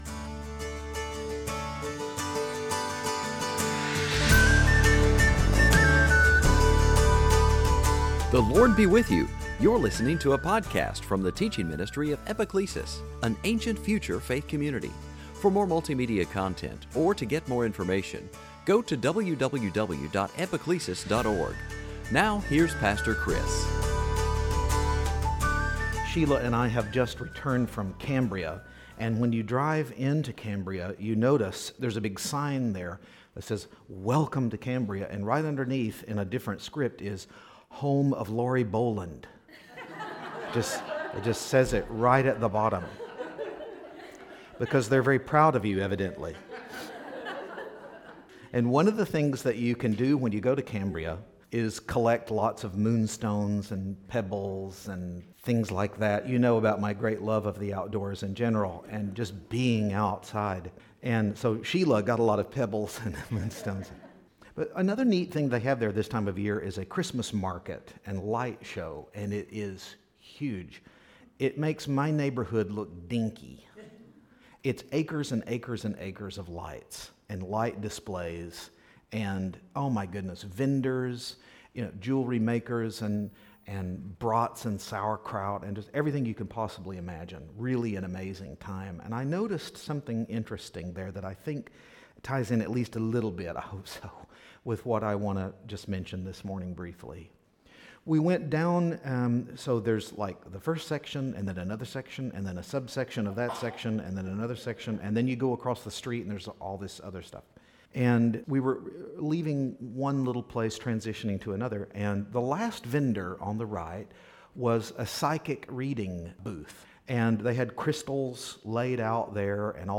Series: Sunday Teaching On the first Sunday of Advent